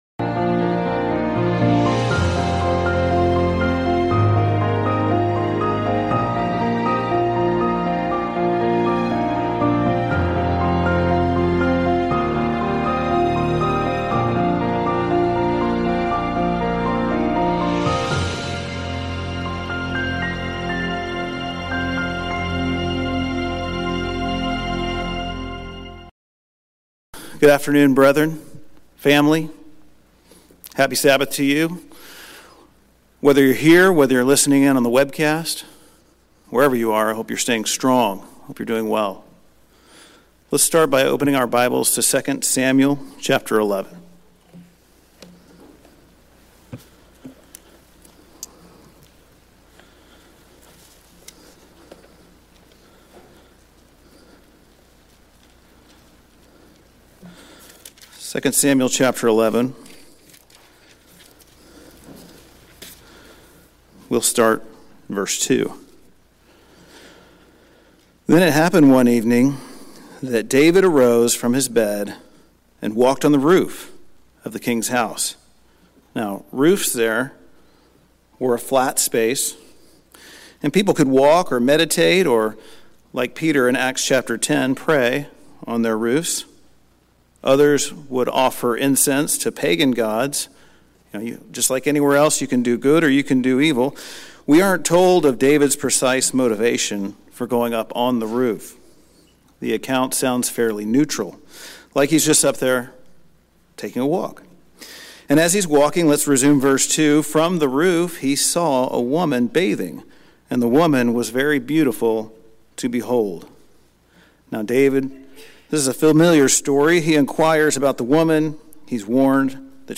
When we think about spiritual warfare, do we imagine (solely) a defensive posture? Using parallels from Joshua's life during Israel's conquest of Canaan, this sermon points to our scriptural responsibility to engage in offensive spiritual warfare and discusses our part in it.
Given in Houston, TX